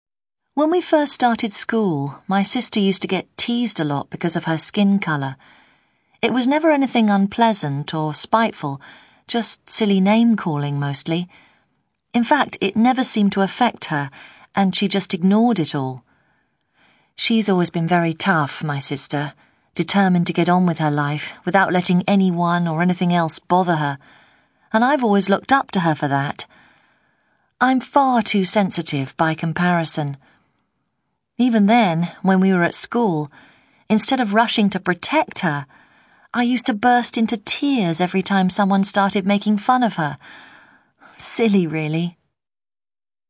You will hear five people talking about their sisters.
SPEAKER 3,